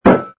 sfx_put_down_glass04.wav